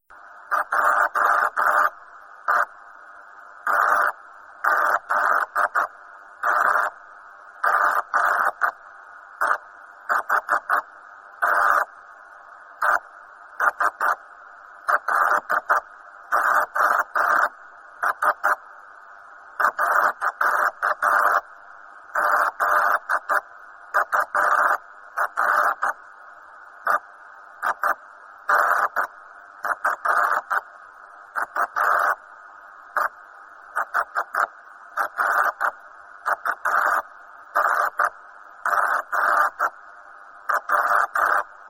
Im Folgenden eine Tonaufnahme parallel zum Kopfhörer, wie im Original nicht sehr laut, denn der Empfänger hat bekanntlich keine Stromversorgung und "lebt" nur von der aufgenommenen Energie der Radiowelle: So tönt(e) es im Kopfhörer
kristall-empfang.mp3